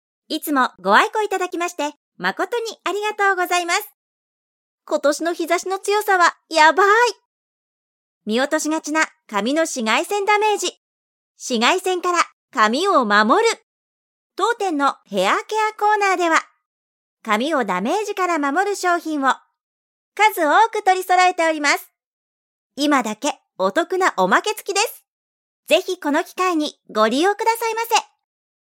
早速、今月追加されたフレーズから、サンプルデータを作ってみました！